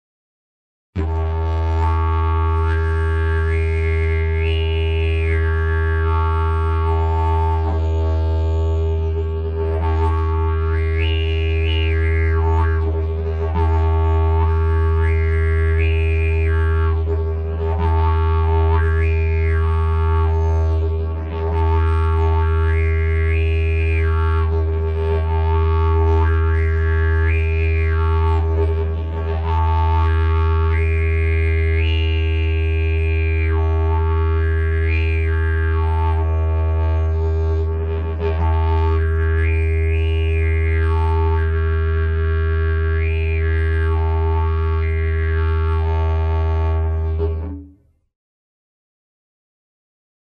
9.1 Armonici
Sample n°6 contiene: applicazione degli armonici su nota base.